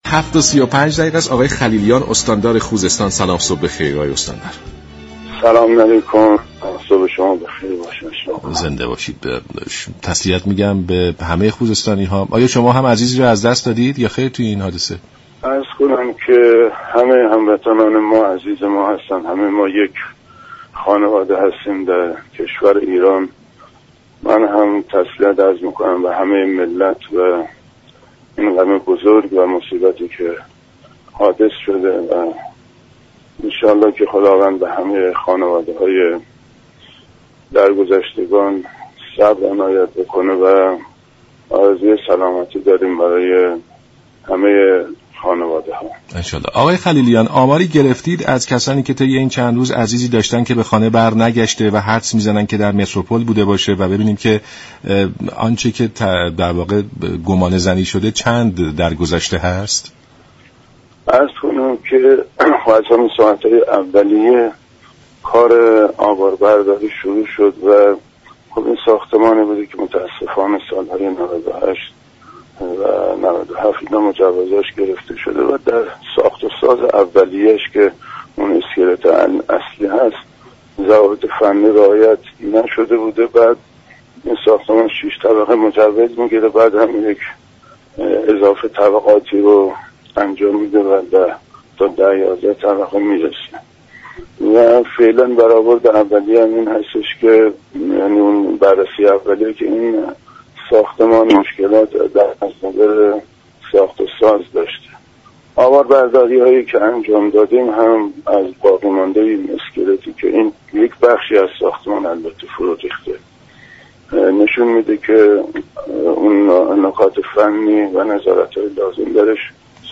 به گزارش شبكه رادیویی ایران، «صادق خلیلیان» استاندار خوزستان، در برنامه «سلام صبح بخیر» به حادثه متروپل آبادان اشاره كرد و گفت: ساختمان متروپل كه مجوز آن در سال 97 و 98 صادر شده است، در ساخت و ساز اولیه ضوابط فنی به درستی رعایت نشده بود.